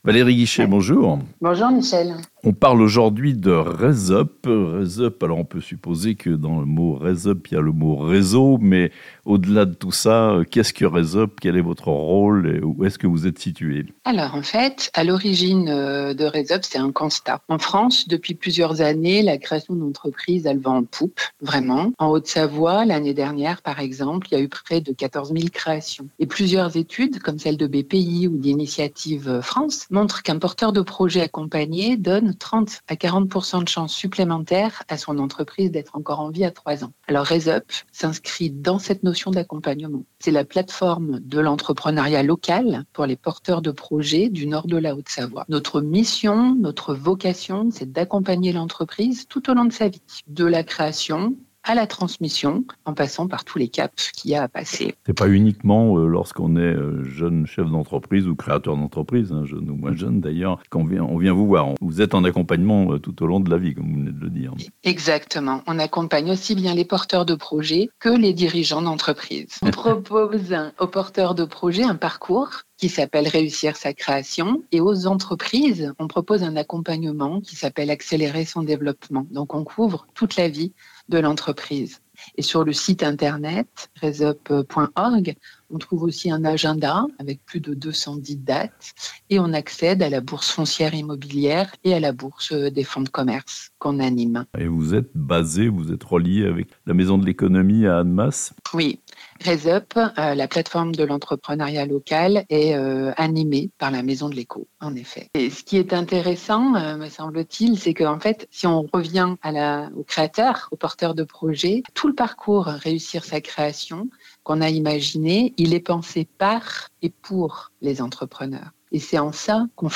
Un podcast en 6 épisodes pour informer et sensibiliser les futurs créateurs d'entreprise (interview)